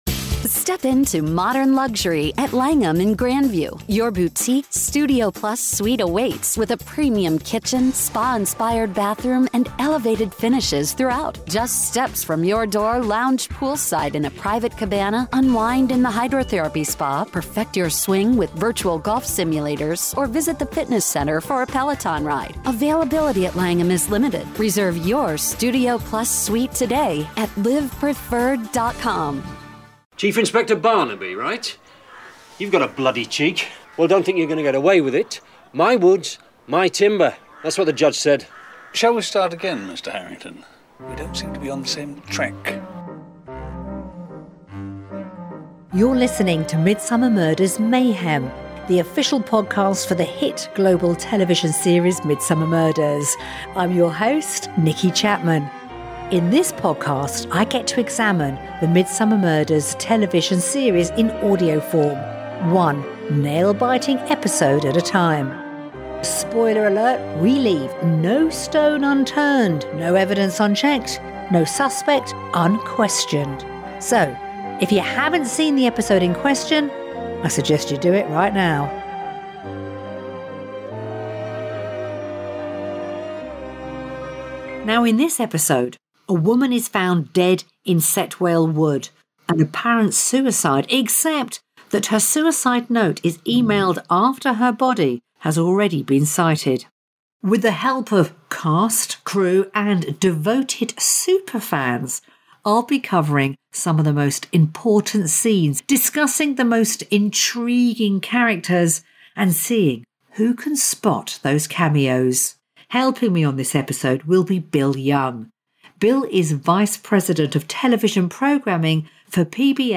Host Nicki Chapman